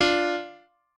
piano4_3.ogg